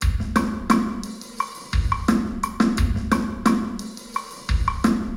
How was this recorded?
All audio examples on this webpage are binaural examples. Big Hall 90° binaural_sdm_big_hall_90deg.wav